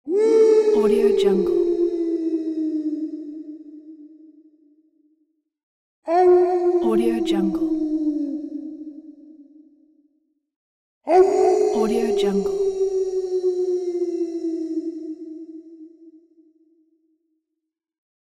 Werewolf Wolf Howl Bouton sonore